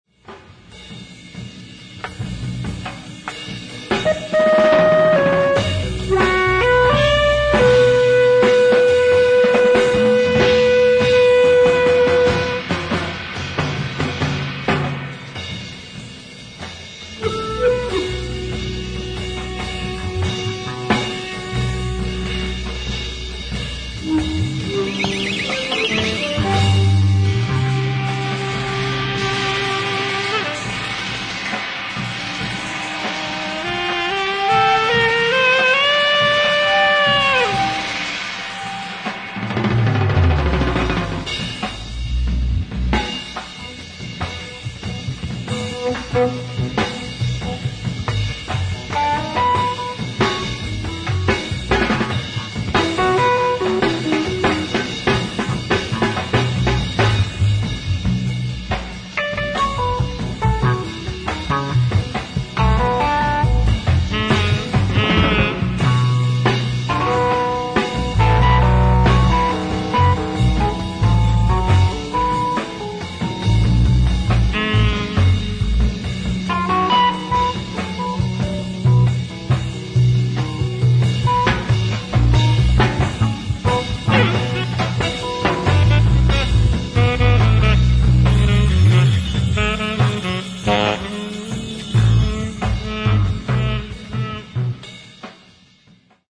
Live At Nagoya, Aichi, Japan 08/05/1973
SOUNDBOARD RECORDING